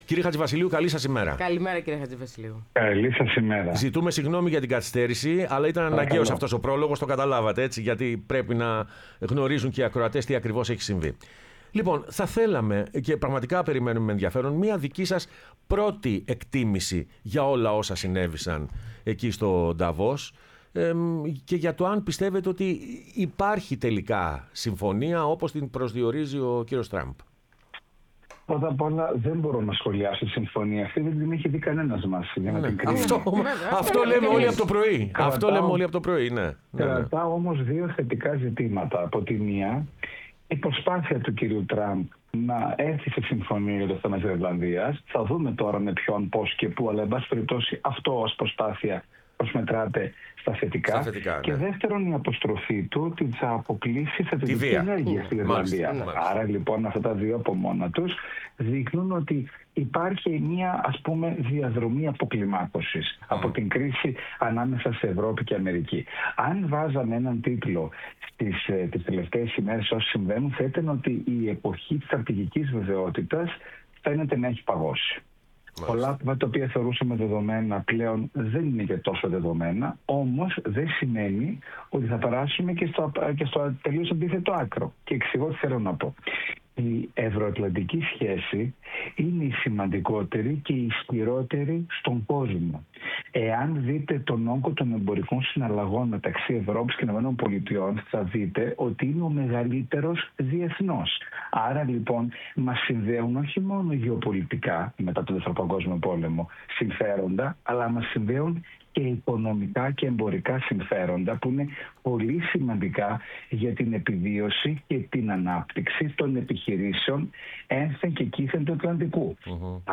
Ο Τάσος Χατζηβασιλείου, διεθνολόγος και βουλευτής ΝΔ, μίλησε στην εκπομπή «Πρωινές Διαδρομές»